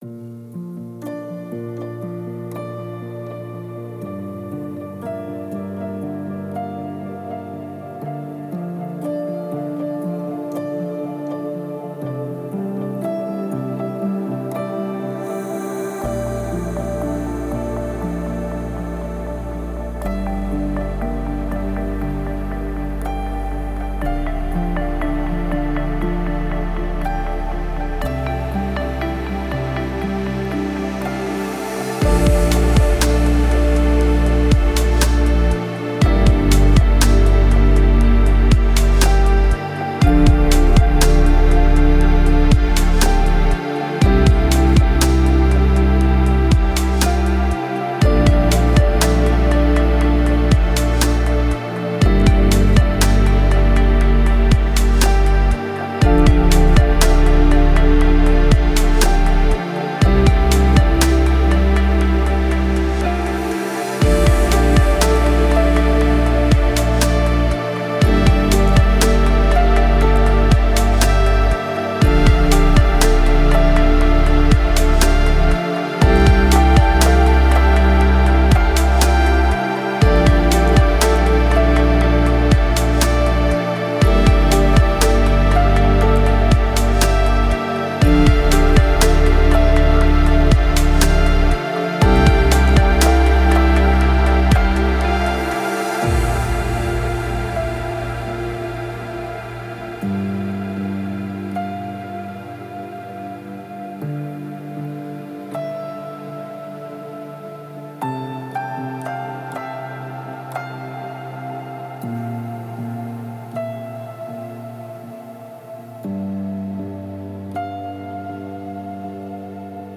Спокойная музыка
релакс музыка
релаксирующие треки